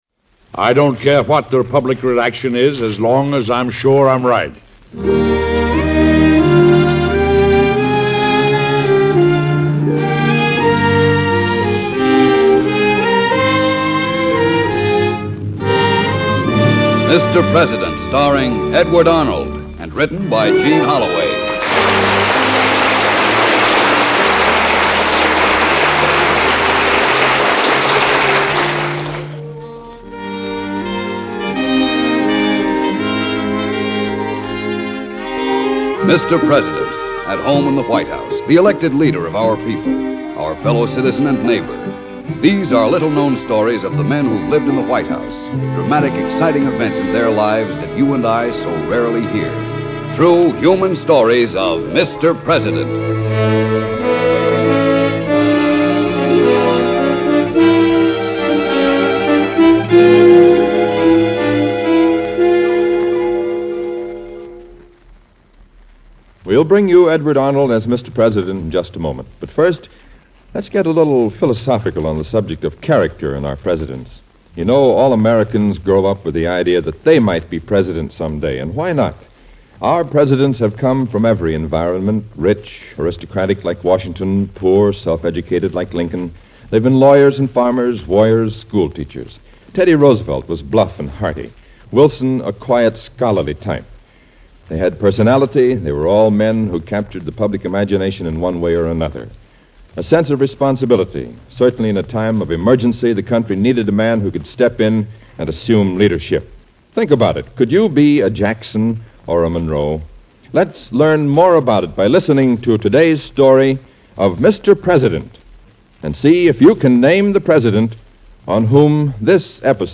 Mr. President, Starring Edward Arnold